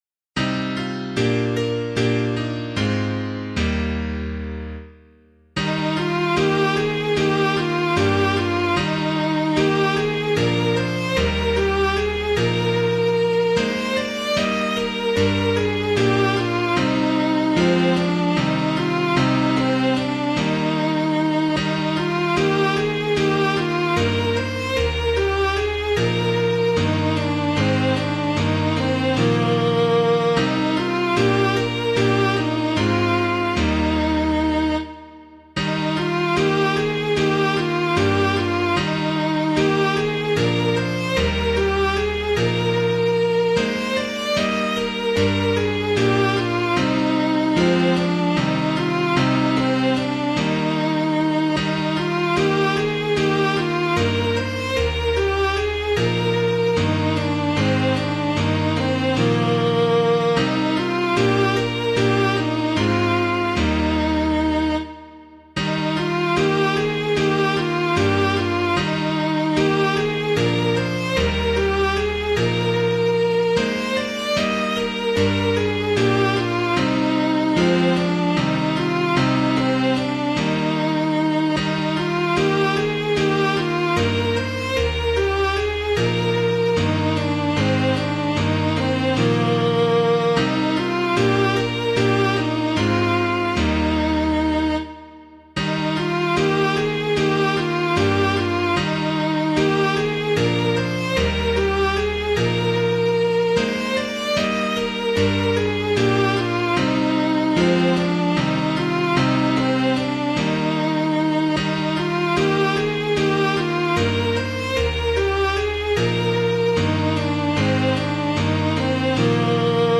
chant, Mode V
piano